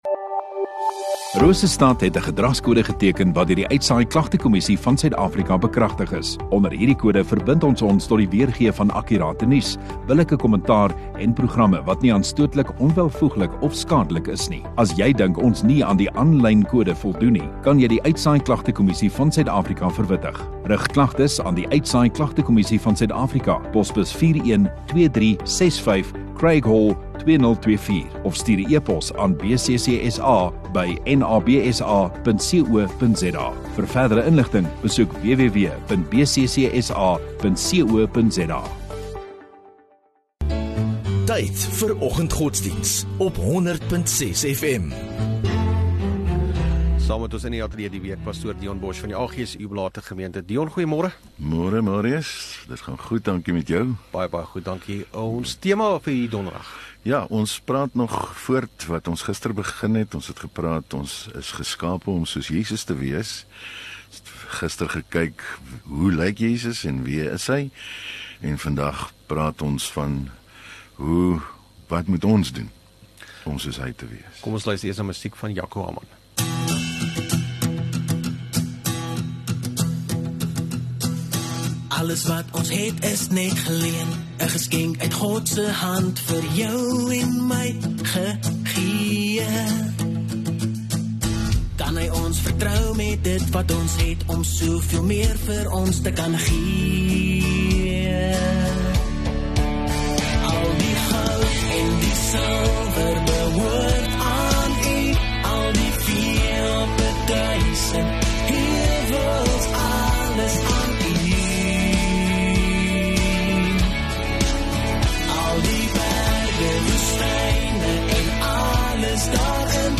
24 Oct Donderdag Oggenddiens